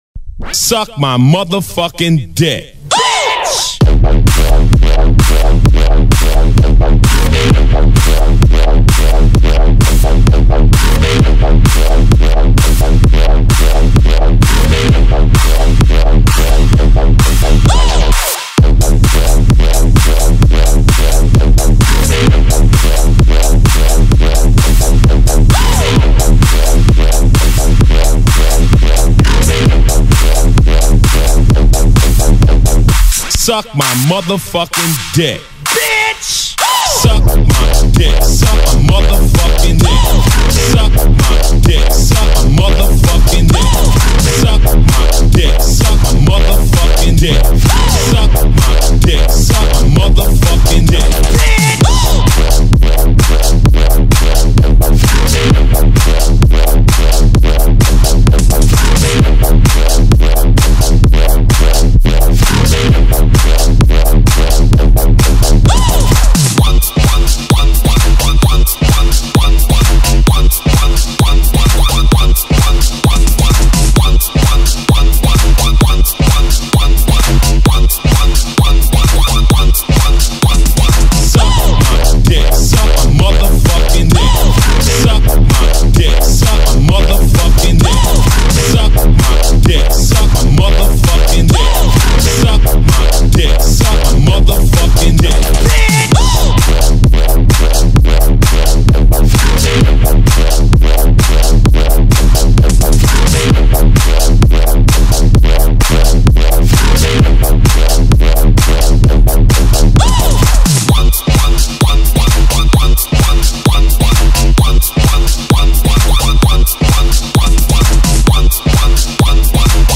basshouse